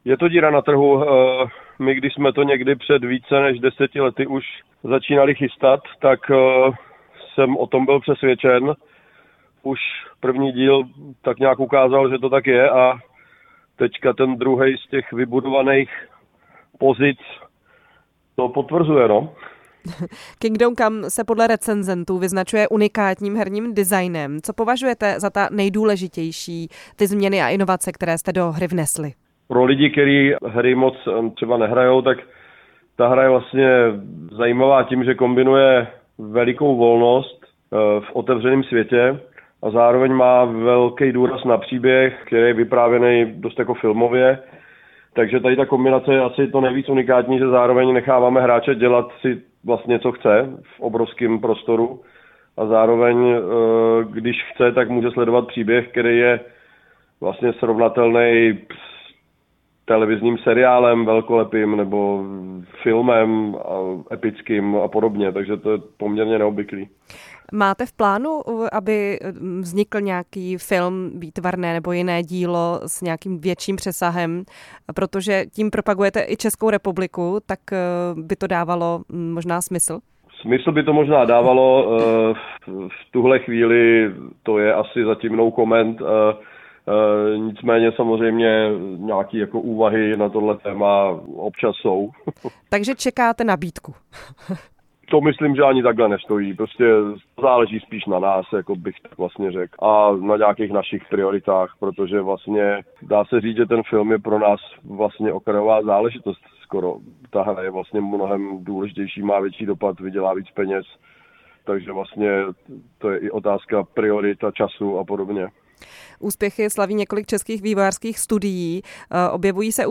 Proč lidi přitahuje takto historicky situovaná hra? Nejen na to odpovídal ve vysílání Radia Prostor Daniel Vávra, kreativní ředitel studia Warhorse.